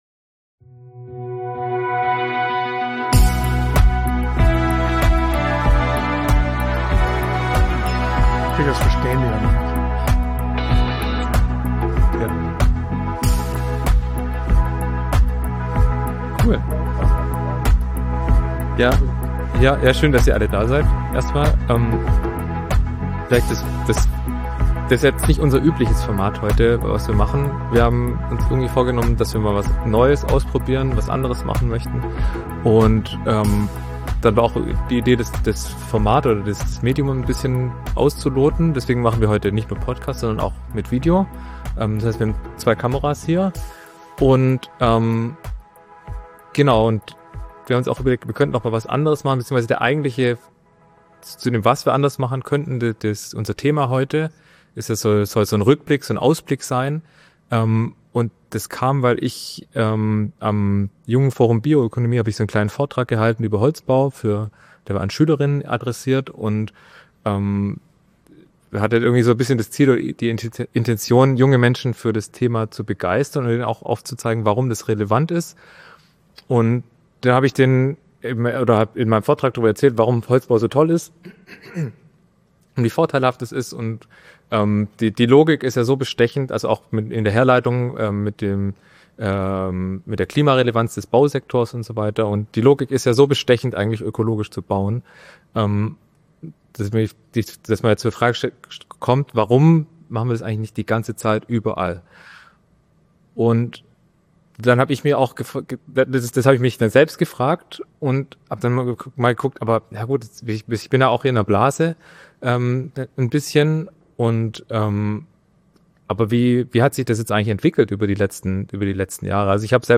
In der heutigen Folge von „Auf Holz bauen" haben wir etwas vollkommen anderes für euch: Wir haben einige unserer liebsten Holzbauexperten zu einem Gespräch eingeladen.